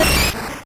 Cri d'Amonita dans Pokémon Or et Argent.